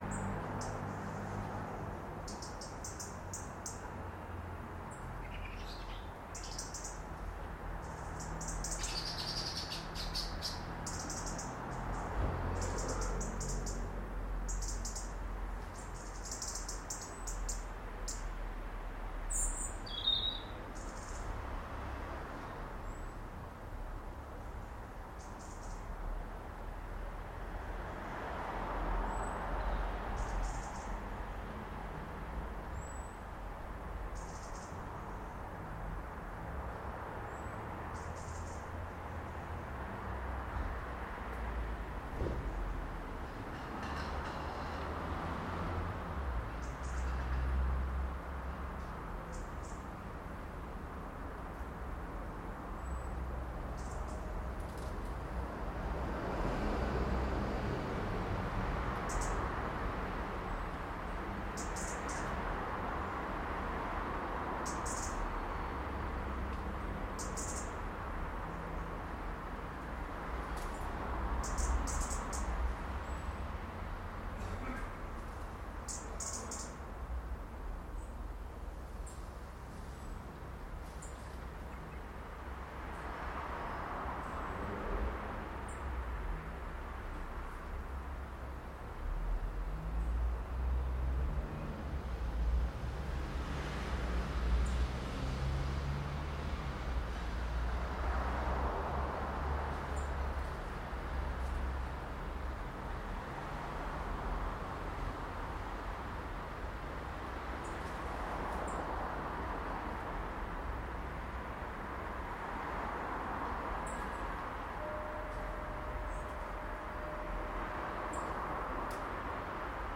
6.30 Birdsong 12 October 2012